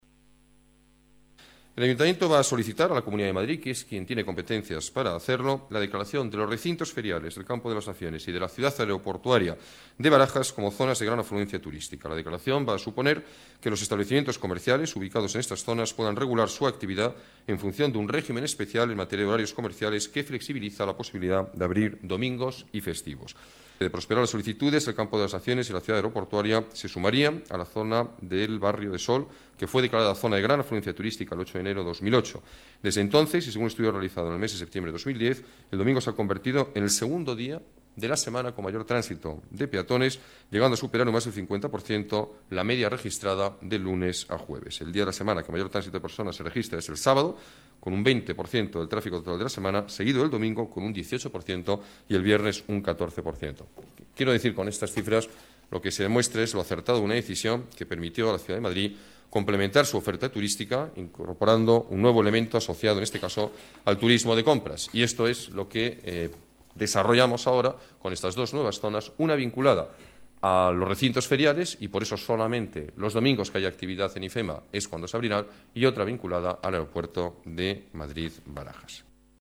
Nueva ventana:Declaraciones del alcalde sobre la solicitud de dos nuevas Zonas de Gran Afluencia Turística